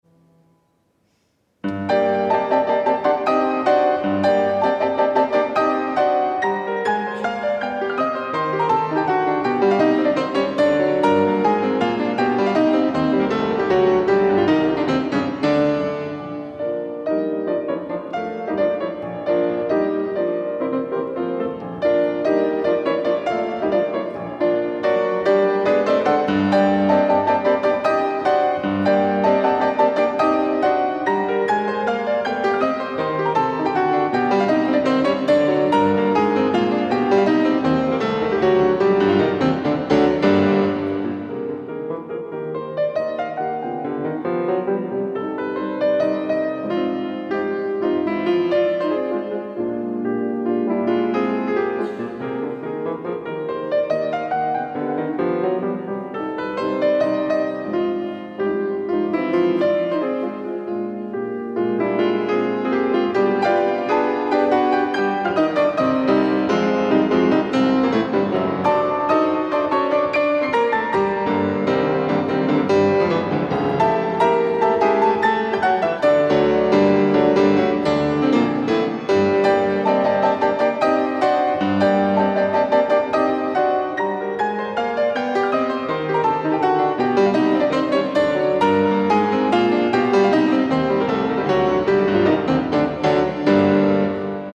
17° Concerto Musica Classica – venerdì 24 novembre 2017 alle ore 20:45 tenuto nella “Sala dei Giganti” dell’Università degli Studi di Padova a Palazzo Liviano (Padova).
Sonata in sol minore op.22 – Scherzo
pianoforte